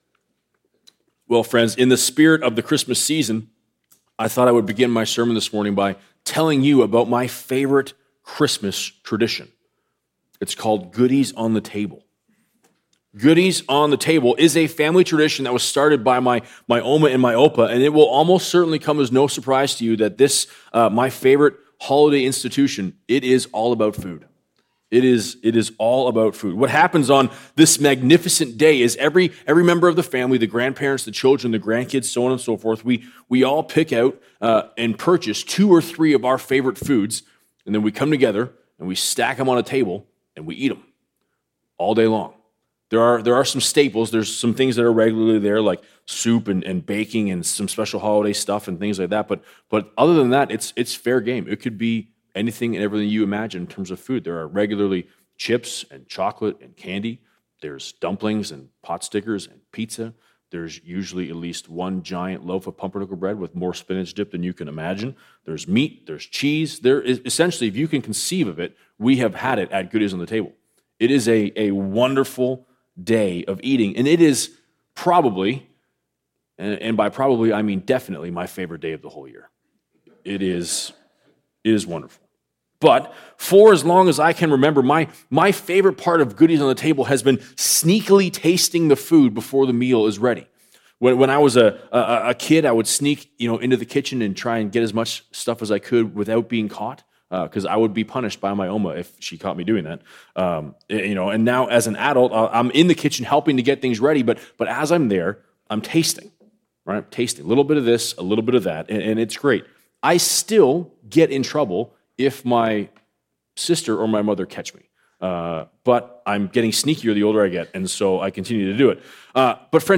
Sermons - Jacqueline Street Alliance Church